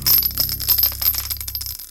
50 Cent Coin FX.wav